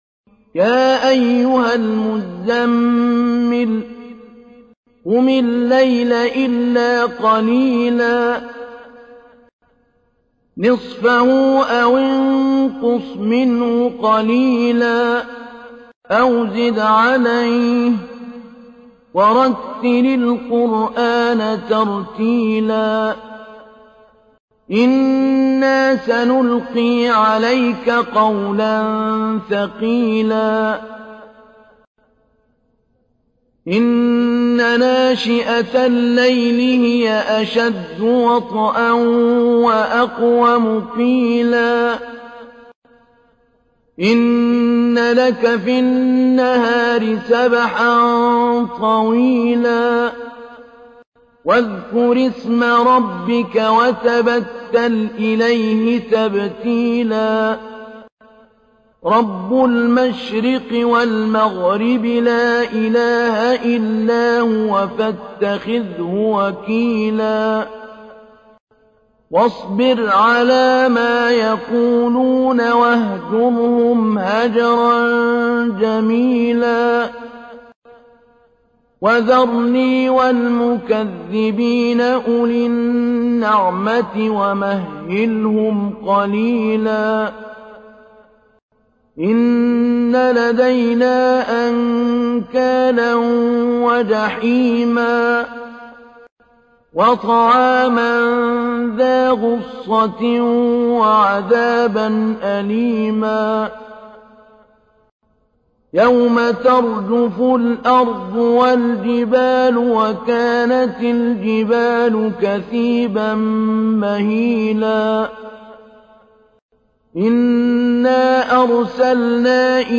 تحميل : 73. سورة المزمل / القارئ محمود علي البنا / القرآن الكريم / موقع يا حسين